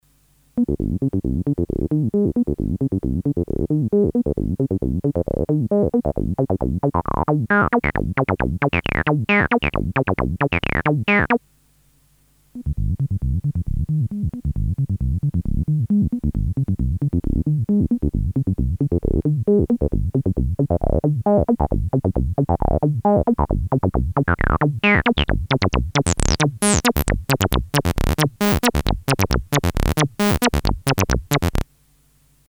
mp3 file demonstrates the extended range of ENV MOD on the modded 303.
Stock then modded 303s play the same pattern, with the ENV MOD control swept over its full range.
The modded 303s range includes the whole range of the original, but goes down to zero and up to more than double the unmodded amount.